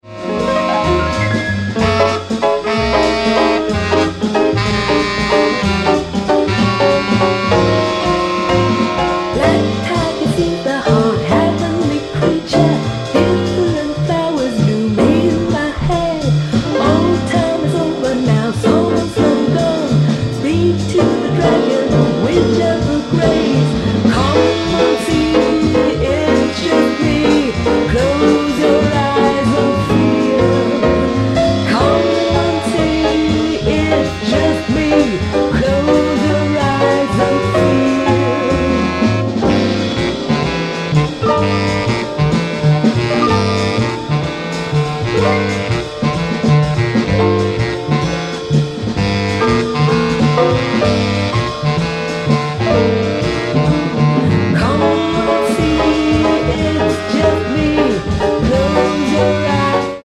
Spiritual and Modal Jazz